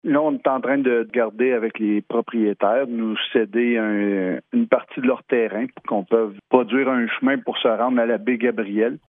L’objectif est d’aménager un chemin qui permettrait d’accéder à un lieu de baignade public. Pour ce faire, les Municipalités doivent absolument obtenir la collaboration des citoyens du secteur, comme le fait valoir le maire de Bouchette, Steve Lefebvre :